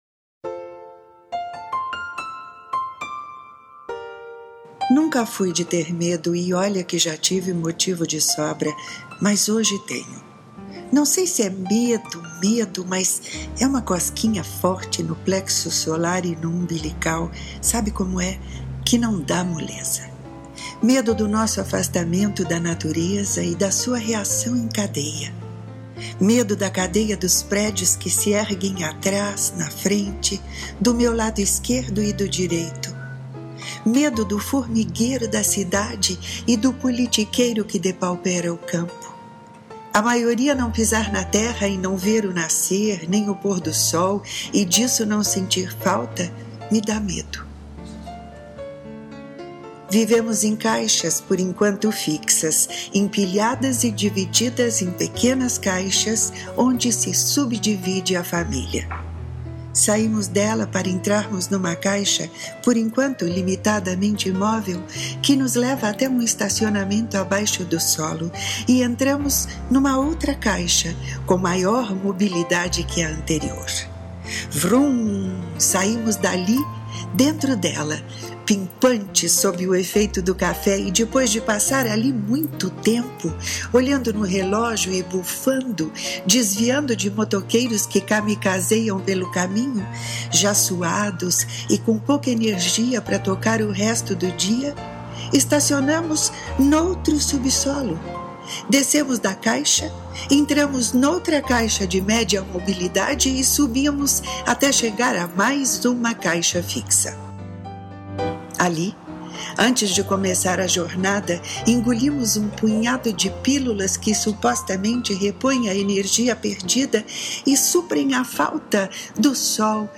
Ouça este texto na voz e sonorizado pela autora